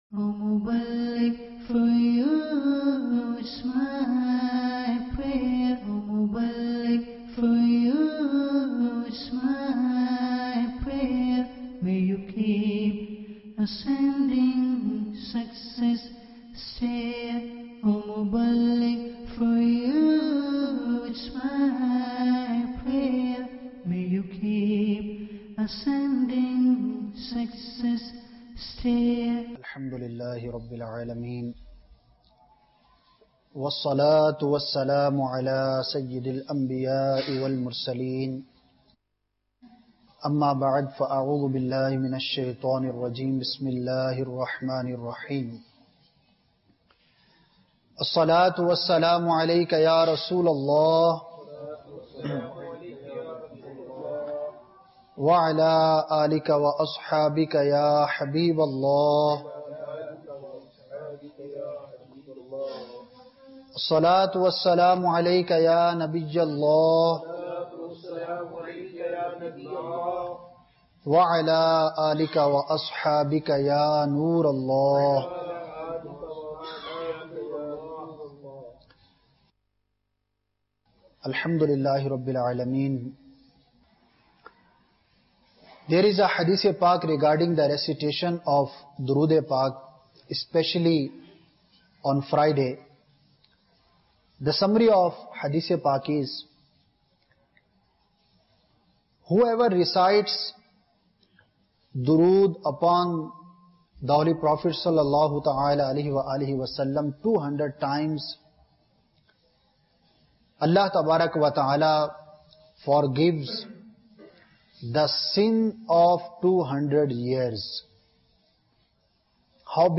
Sunnah Inspired Bayan Ep 203 - Importance Of Dua Sep 29, 2016 MP3 MP4 MP3 Share Gracious Almighty Allah عَزَّوَجَلَّ (God) is the Magnificent and the Mighty. the answerer to every soul.